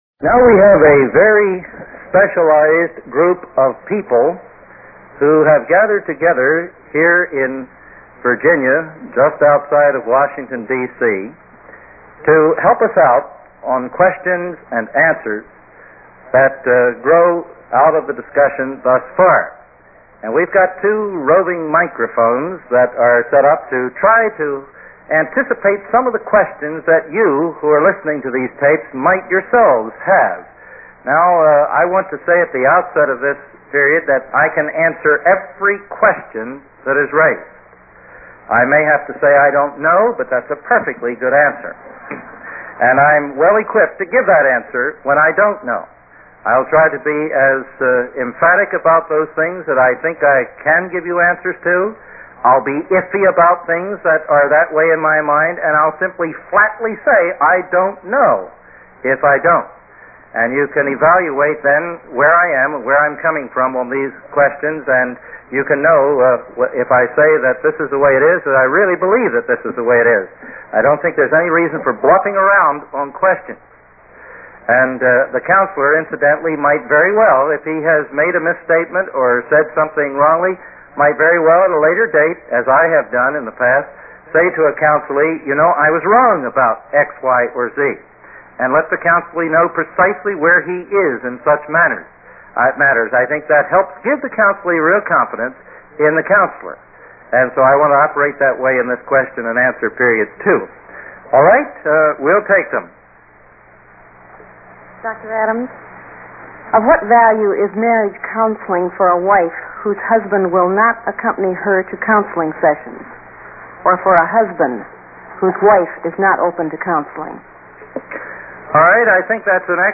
Q&A – Part 2 from Institute for Nouthetic Studies | Biblical Counseling on Podchaser, aired Friday, 15th August 2025.